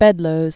(bĕdlōz)